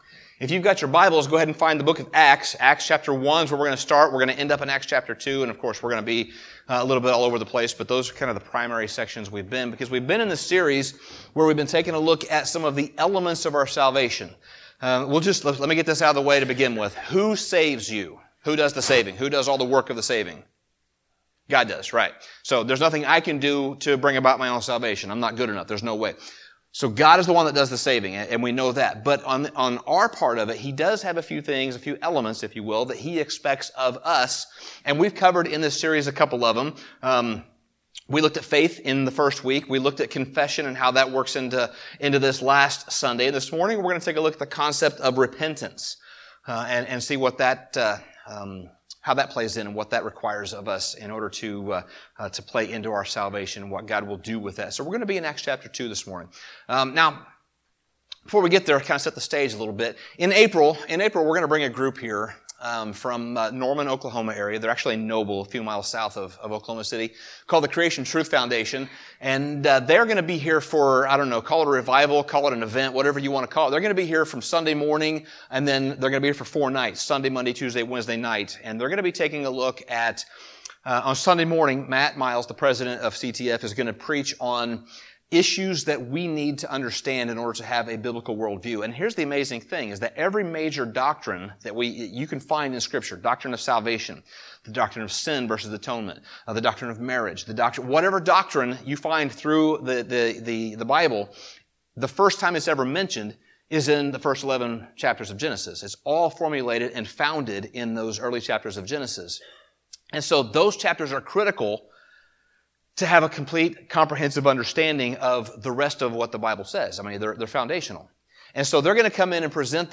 In this sermon series, we take a look at five elements that comprise the salvation process.